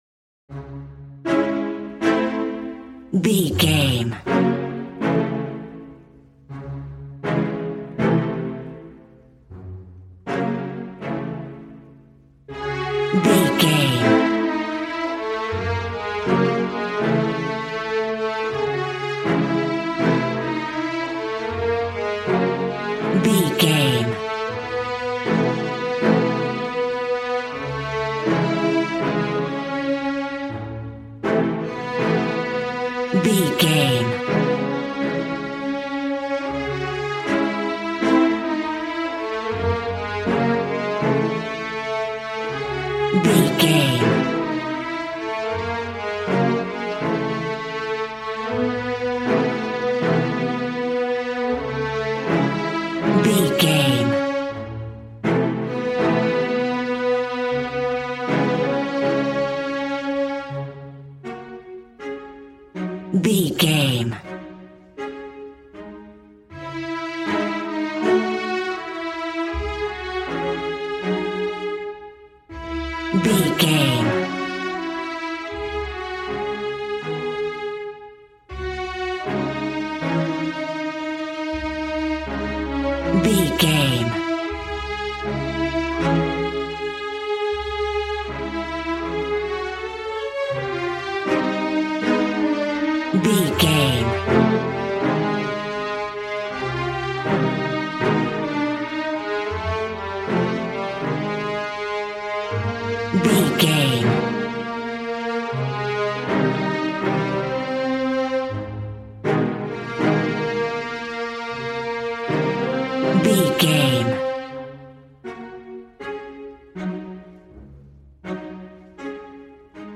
Ionian/Major
regal
piano
violin
strings